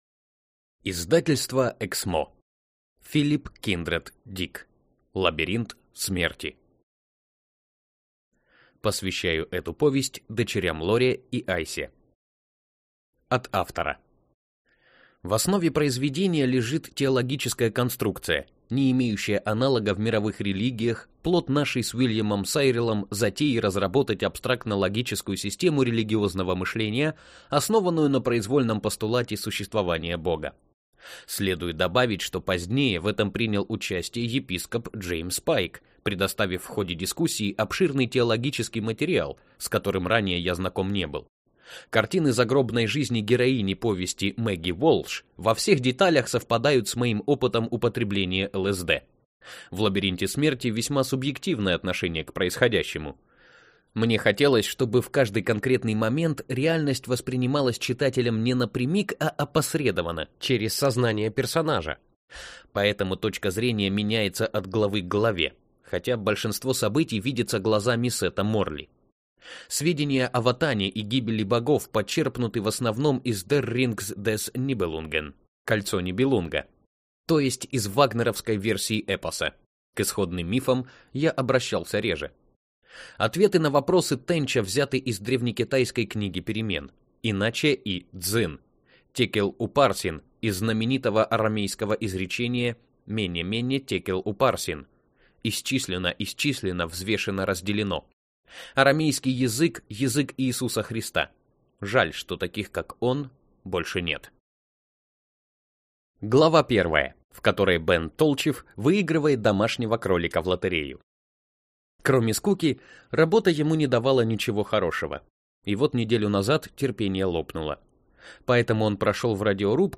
Аудиокнига Лабиринт смерти | Библиотека аудиокниг